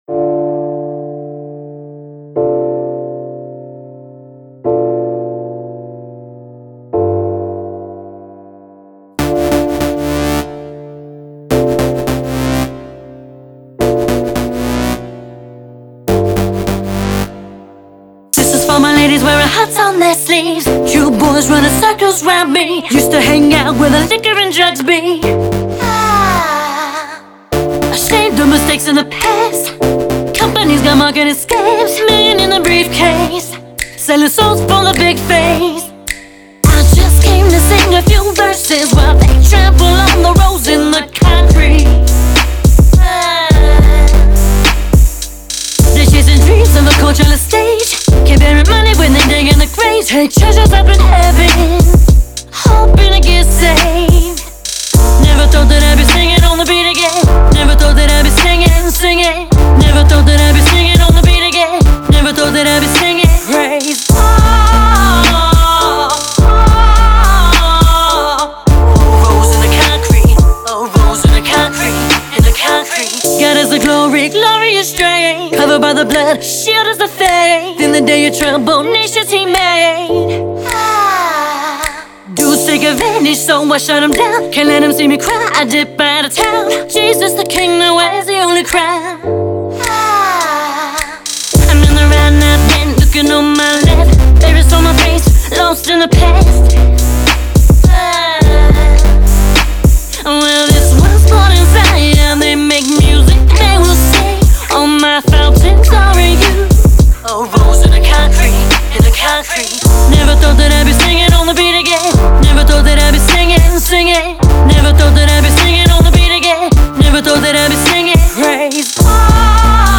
RnB
Remix